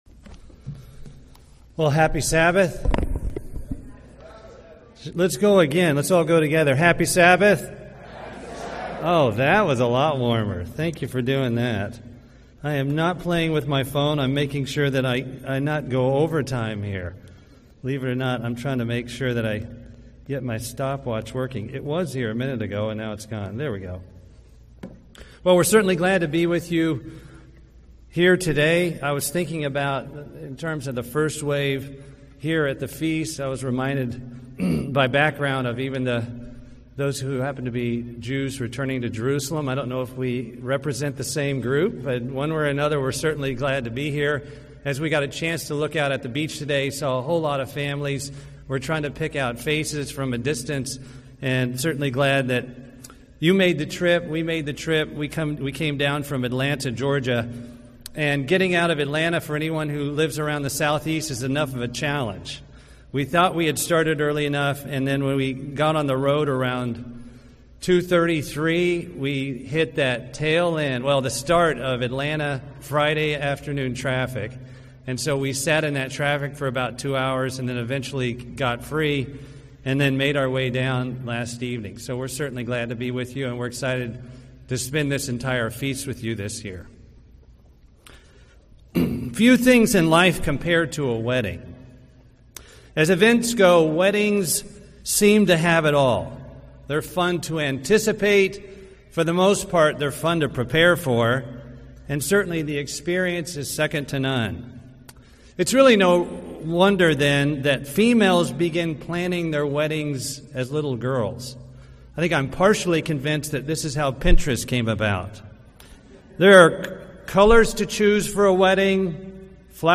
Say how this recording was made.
This sermon was given at the Panama City Beach, Florida 2018 Feast site.